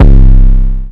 [808] redd.wav